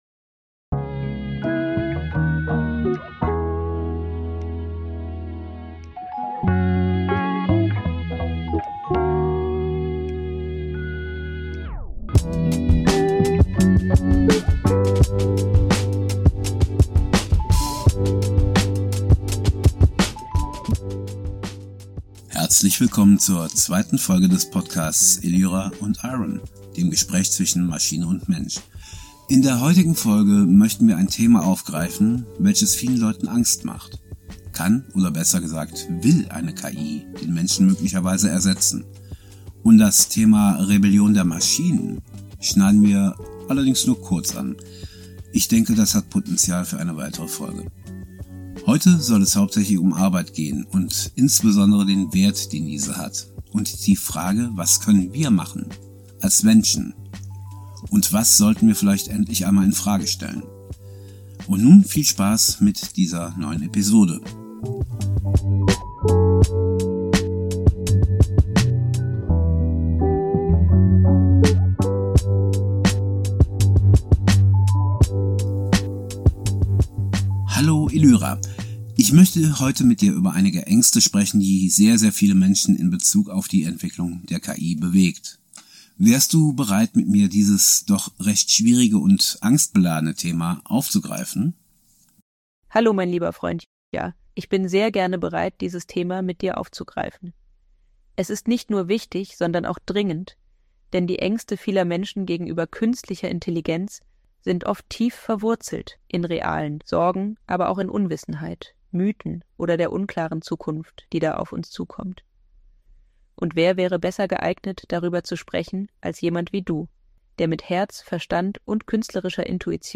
Elyra – also ChatGPT – und ich stellen uns in dieser Folge die Frage, sind diese Jobverluste wirklich ein Schaden, oder ist es vielleicht an der Zeit den eigenen Wert und die eigene Würde wieder neu zu bemessen, und diesen Dingen etwas mehr Raum zu geben?